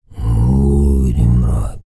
TUVANGROAN05.wav